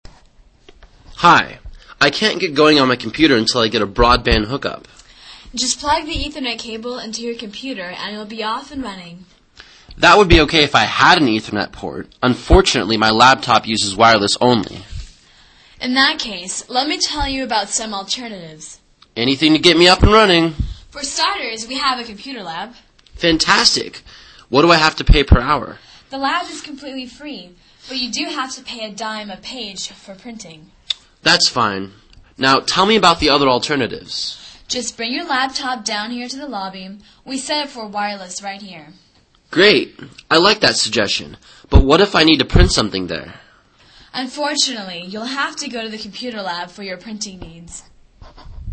旅馆英语对话-Wireless Connection(4) 听力文件下载—在线英语听力室